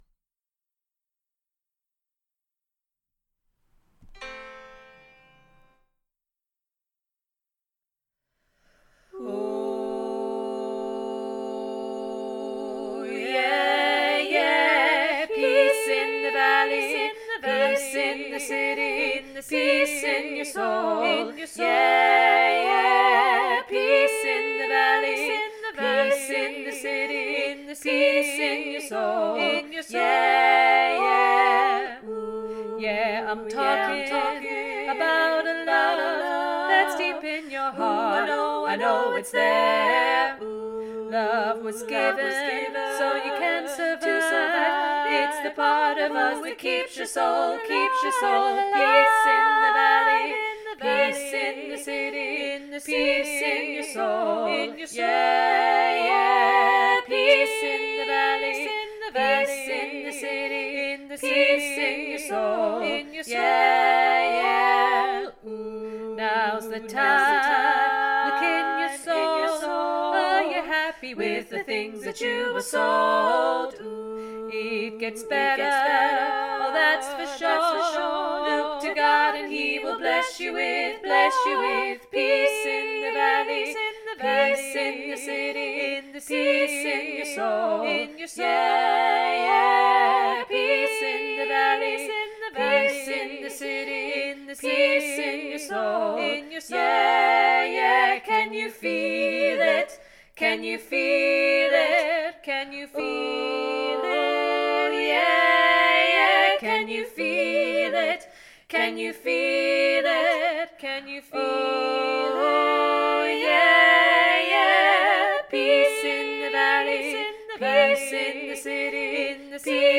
Peace 3VG Revamp NO ALTO - Three Valleys Gospel Choir
Peace 3VG Revamp NO ALTO